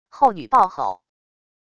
后女暴吼wav音频